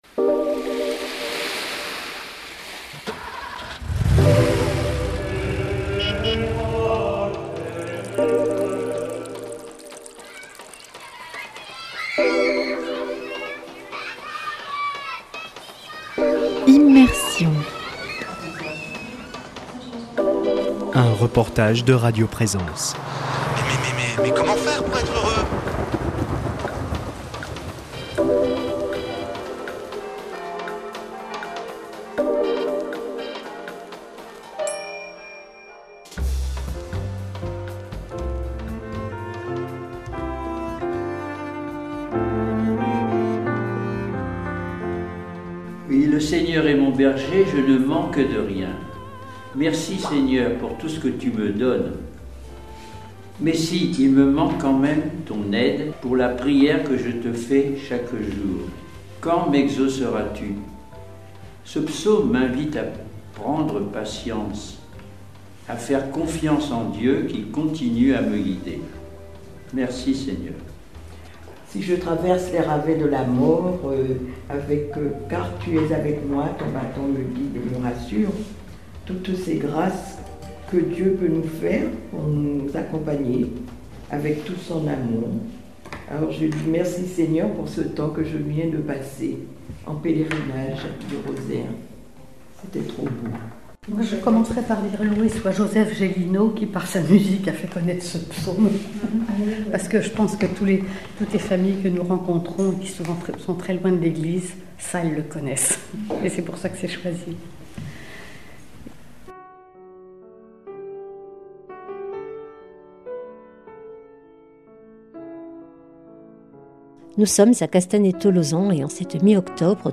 [ Rediffusion ] Mi-octobre, à Castanet-Tolosan l’équipe paroissiale du service des Funérailles se retrouve comme chaque mois à la Maison de la Fraternité. L’ambiance est paisible et…fraternelle, on échange des nouvelles, on partage des joies, on s’écoute, on s’épaule et on se nourrit des expériences des uns et des autres…Une impérieuse nécessité, un besoin vital pour les membres de ce service, pour celles et ceux qui au nom d’une communauté accompagnent les morts et espèrent avec les vivants.